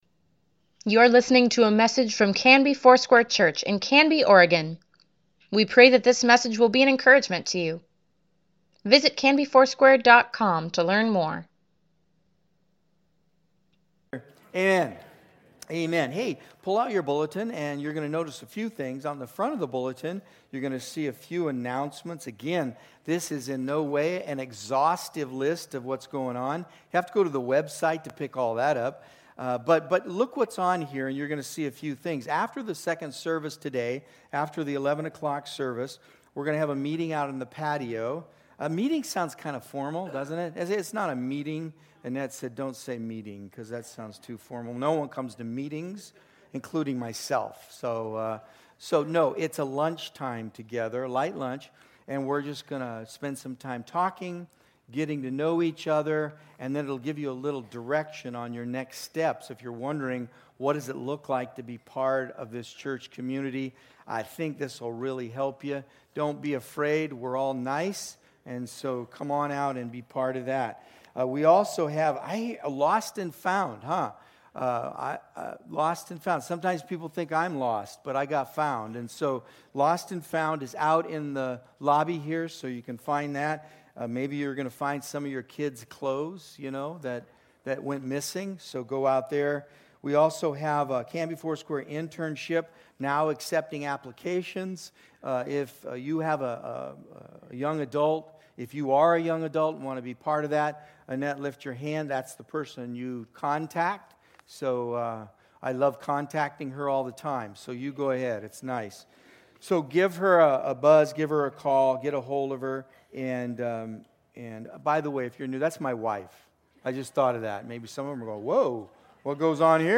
Weekly Email Water Baptism Prayer Events Sermons Give Care for Carus 1 John, pt. 10 January 19, 2020